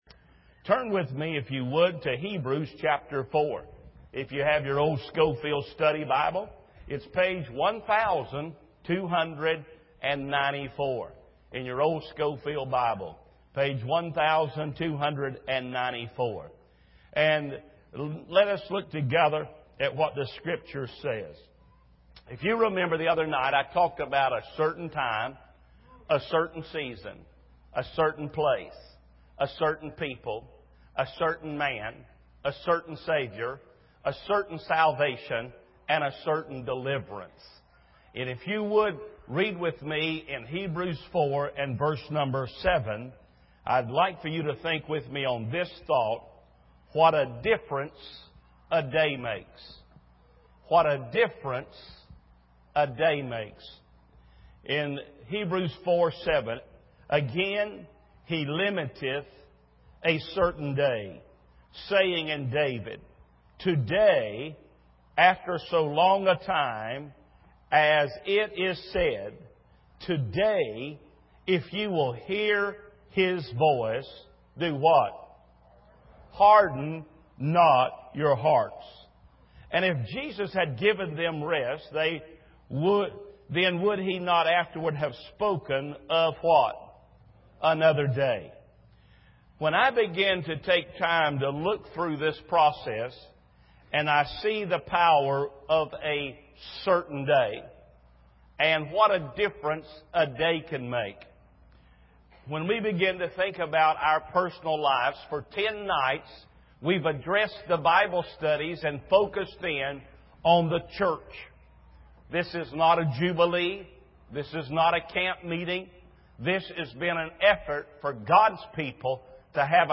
In this sermon, the speaker begins by directing the audience to turn to Hebrews chapter 4 in their Bibles.